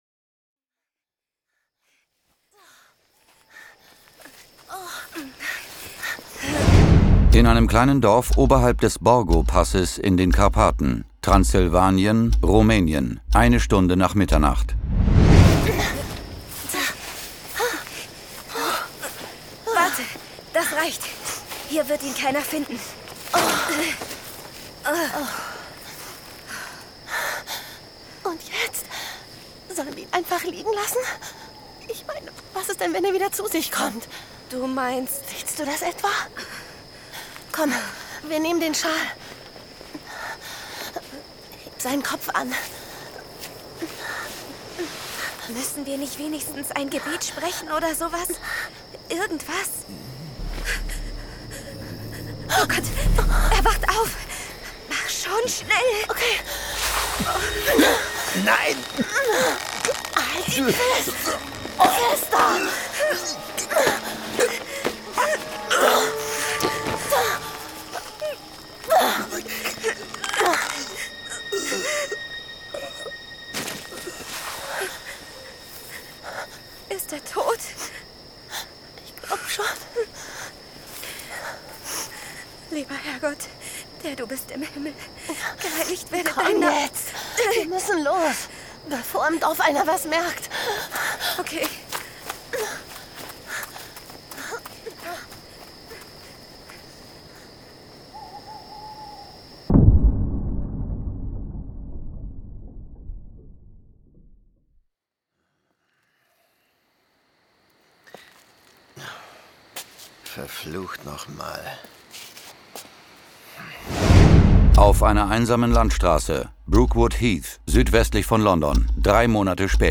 John Sinclair Classics - Folge 15 Die Bräute des Vampirs. Hörspiel.